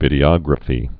(vĭdē-ŏgrə-fē)